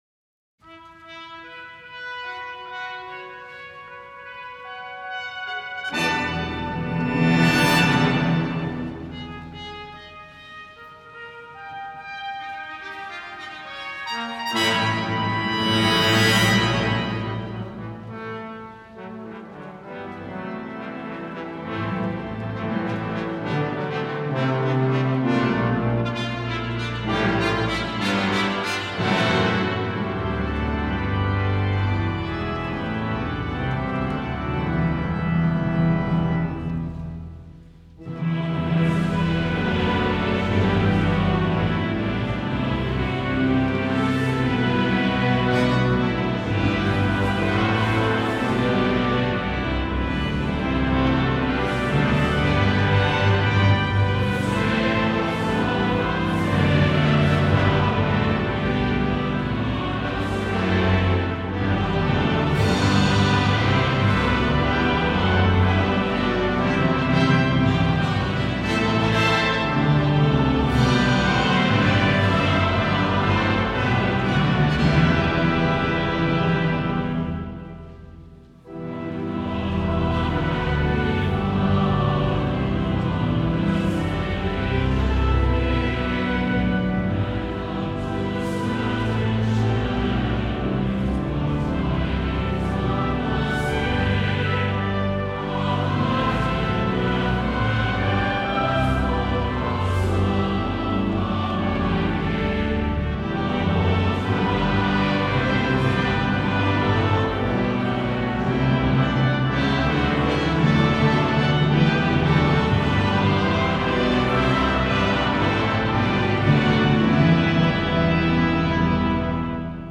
Voicing: Congregation, SATB, Brass Quartet, and Organ Level